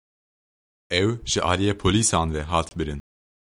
Pronounced as (IPA) /bɪˈɾɪn/